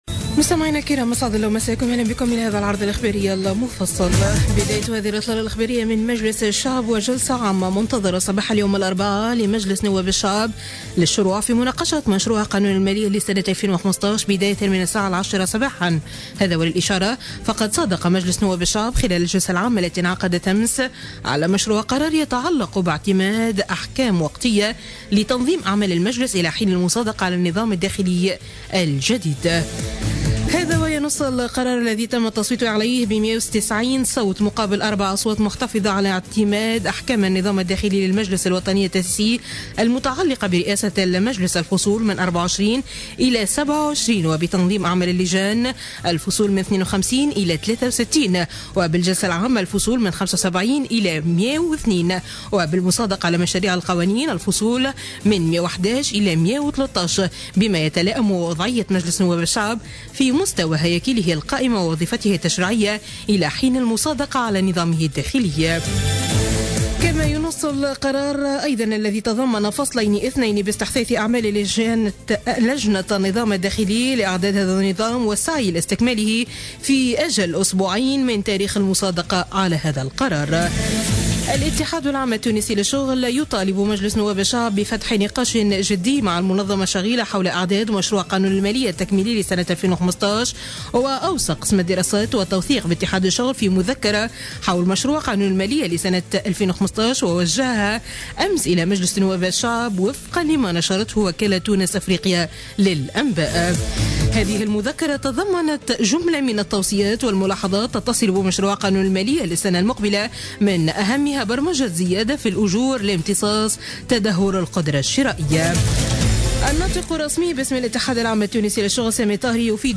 نشرة أخبار منتصف الليل ليوم 10-12-14